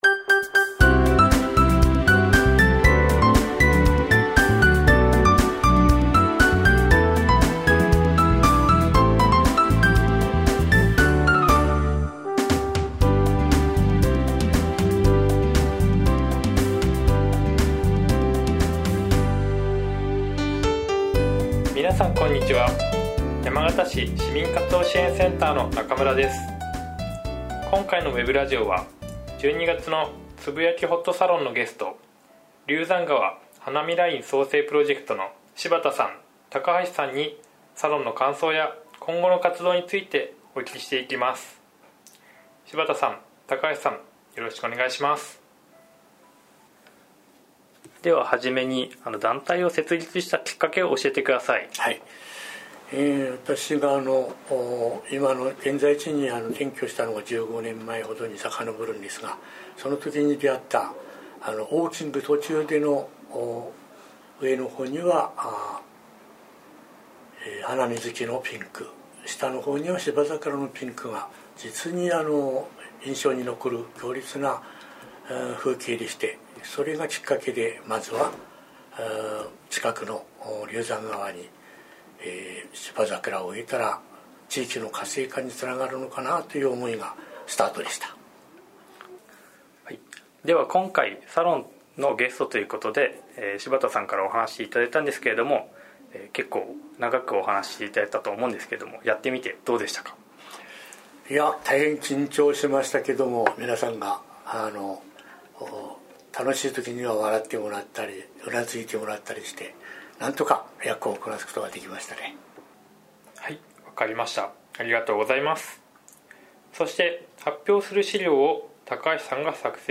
ウェブラジオ2018年12月